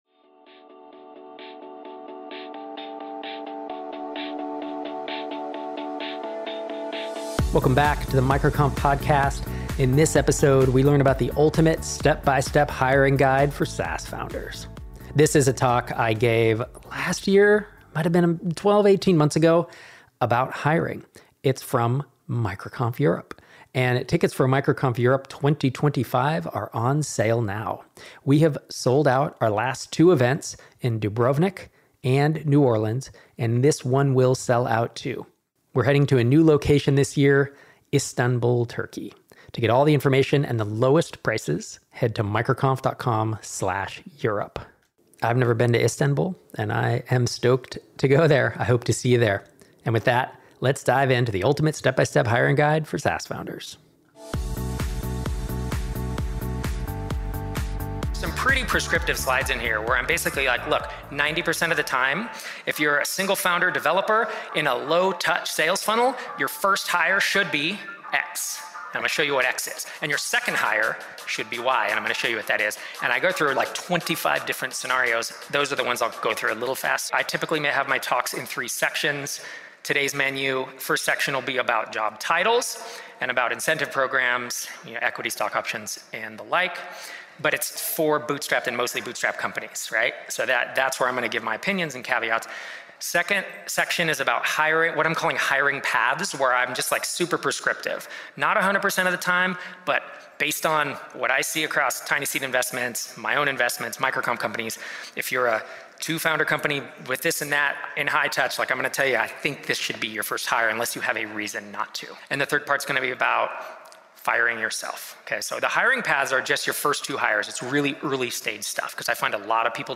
speaks from the stage at MicroConf US 2023 to share how to hire your SaaS team. He covers job titles & incentives, hiring paths, and how to fire yourself - all with a focus on bootstrapped and mostly bootstrapped companies.